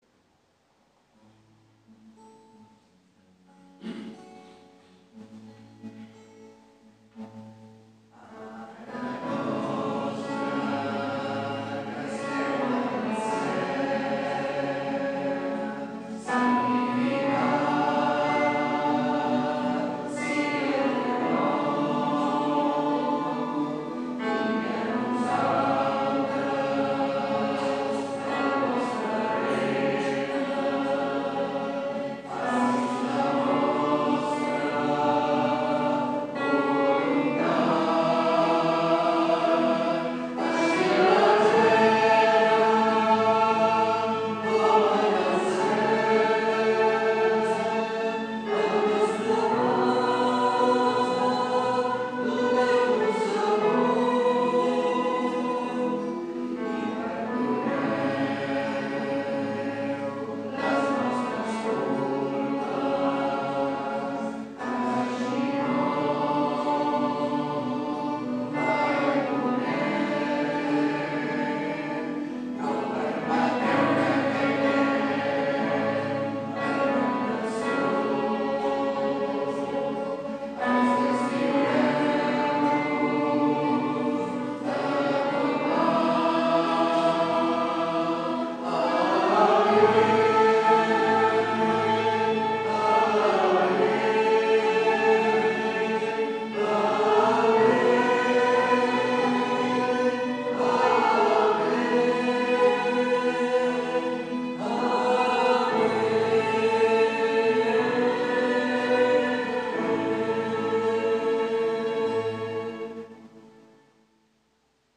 Pregària de Taizé
Ermita de Sant Simó - Diumenge 26 de gener de 2014